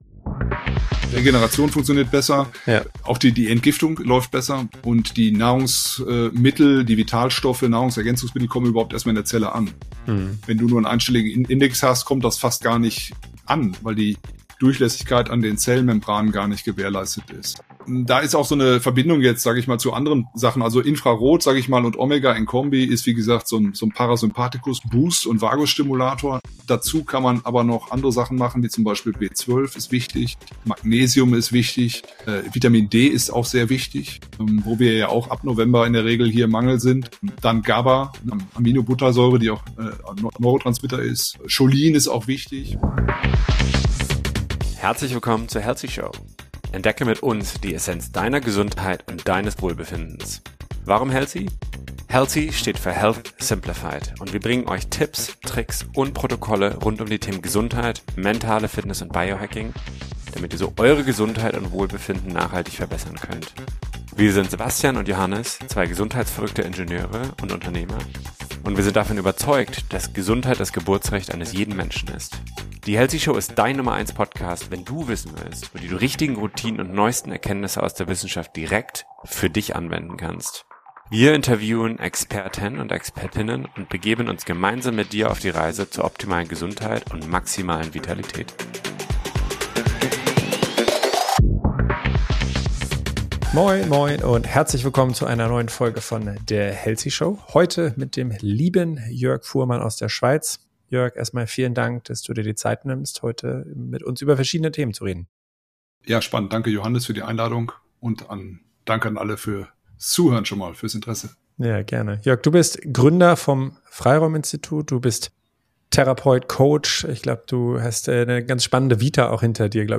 Was haben dein Nervensystem, deine Gesundheit und Resilienz gemeinsam? In dieser Folge erwartet dich ein tiefgehendes Gespräch